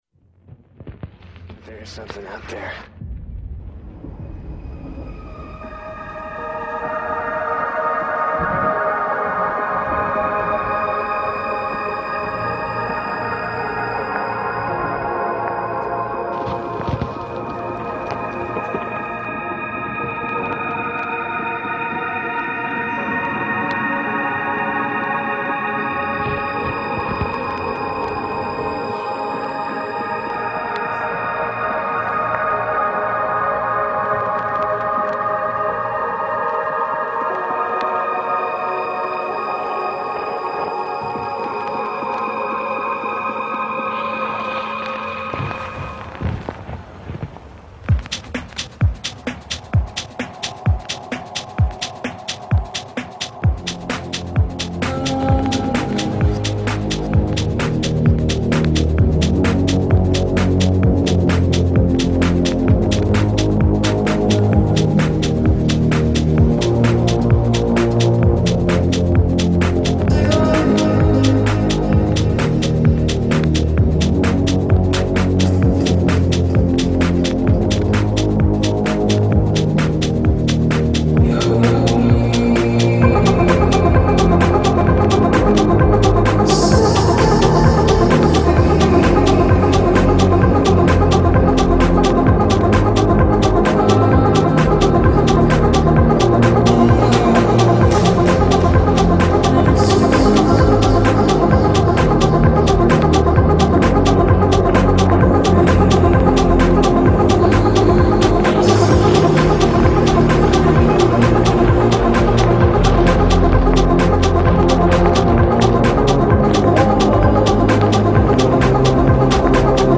UK electronic artist
The music is sad but also sensual.